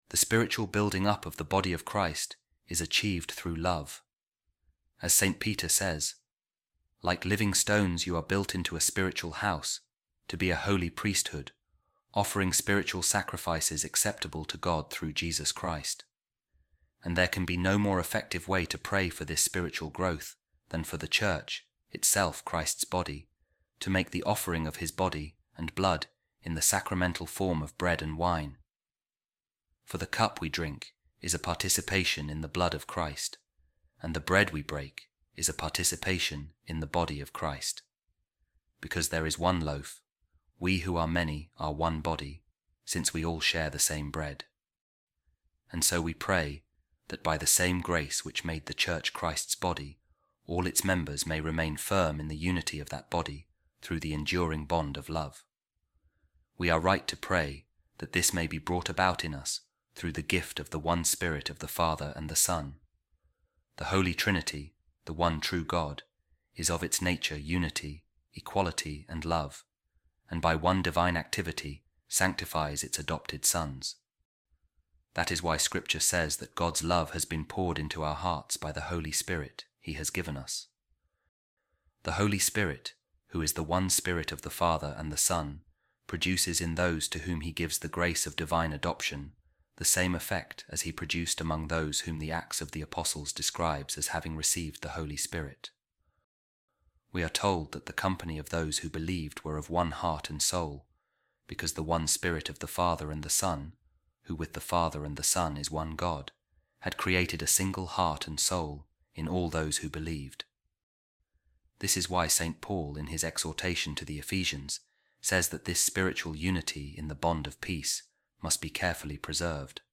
A Reading From The Books Of Saint Fulgentius Of Ruspe Addressed To Monimus